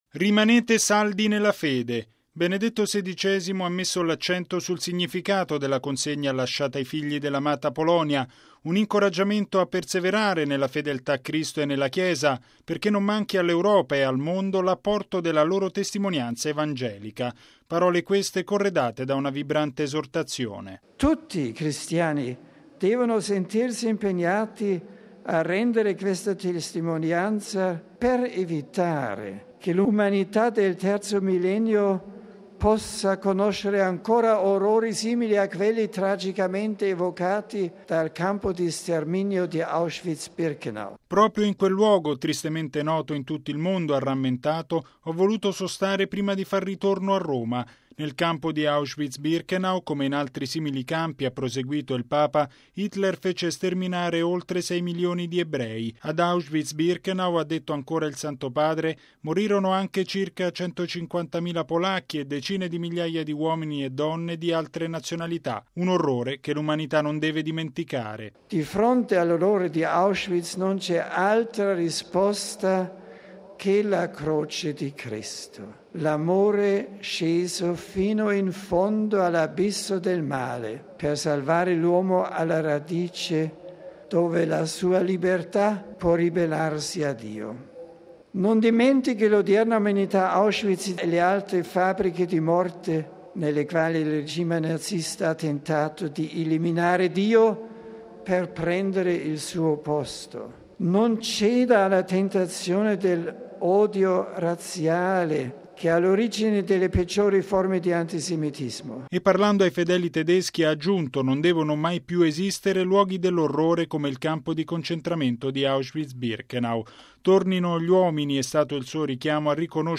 (31 maggio 2006 - RV) L’umanità non dimentichi l’orrore di Auschwitz-Birkenau e delle altre fabbriche della morte naziste: è il vibrante richiamo di Benedetto XVI all’udienza generale di stamani, in piazza San Pietro. Un’udienza, a cui hanno preso parte 35 mila pellegrini, e dedicata dal Papa interamente al suo viaggio apostolico in Polonia.